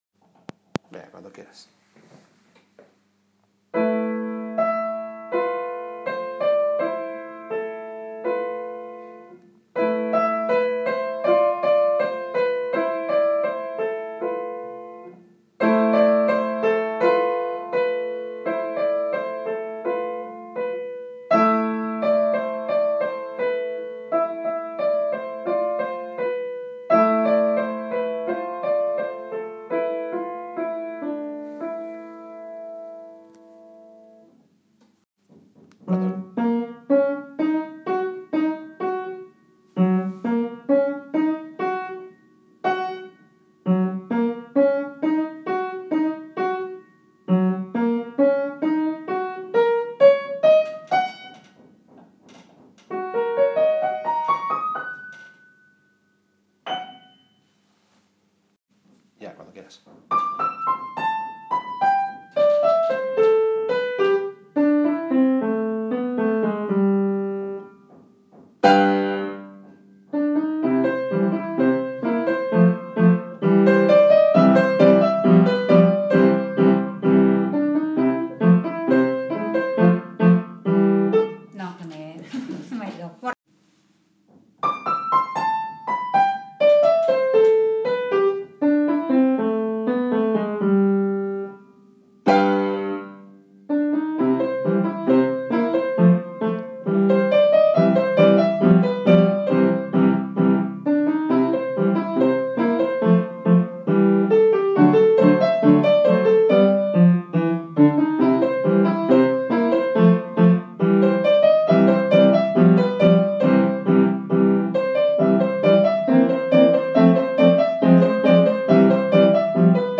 musica piano